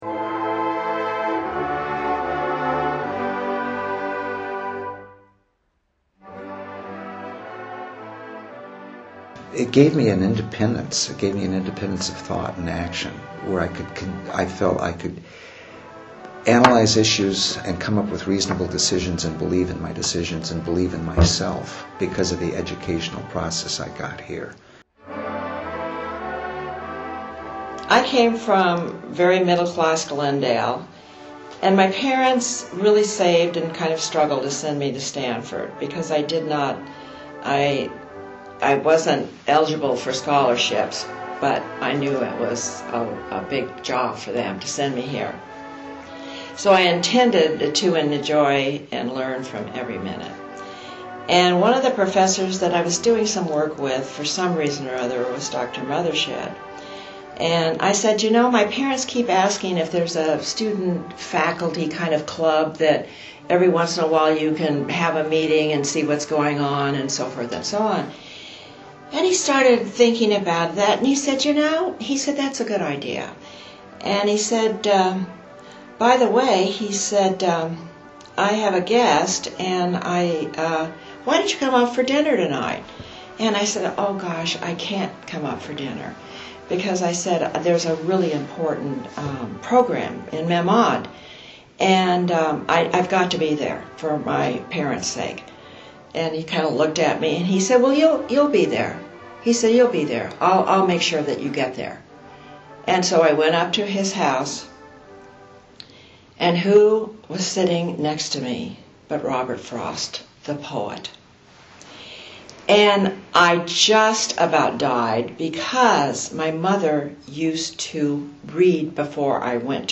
Oral History
The Alumni Stories project was piloted during Reunion Homecoming Weekend in October 2007, supported by a grant from the Stanford Associates, and was repeated in October 2008. Over 60 alumni, most attending their 50th reunions, recounted their memories and stories about undergraduate student life in the 1950s.